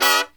FALL HIT03-L.wav